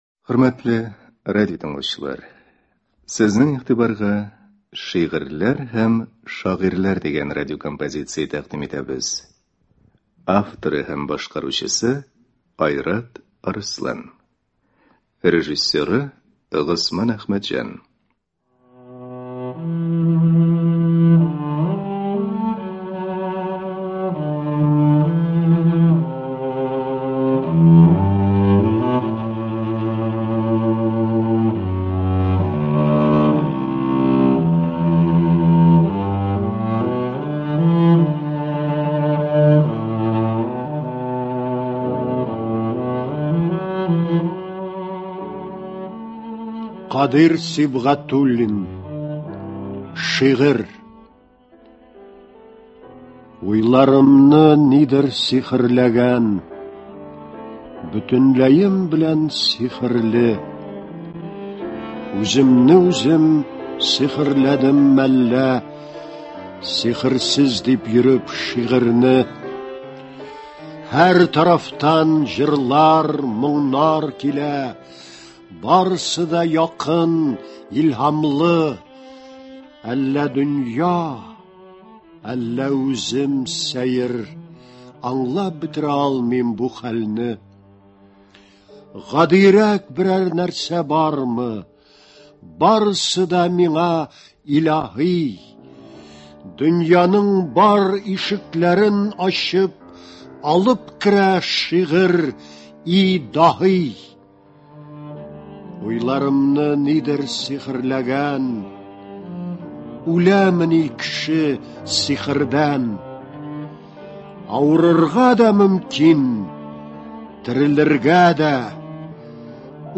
«Шигырьләр һәм шагыйрьләр». Радиокомпозиция.